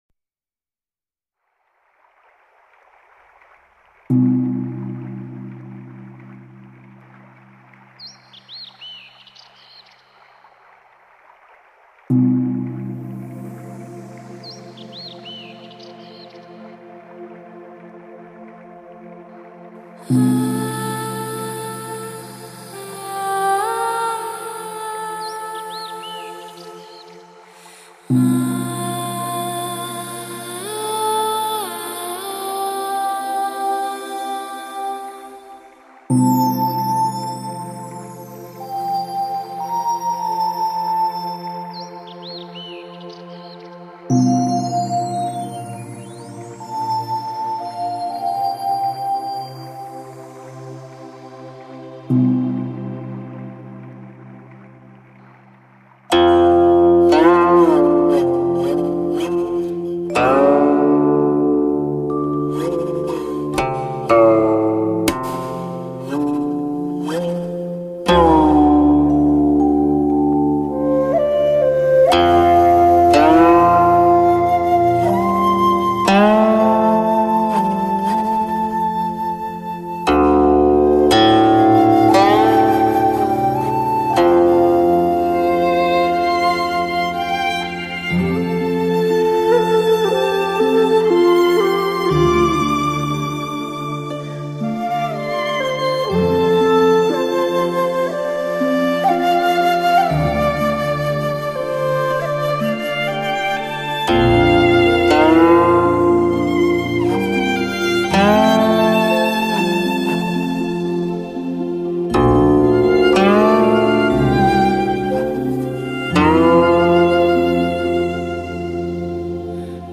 箫声幽咽，古琴声声，好碟！~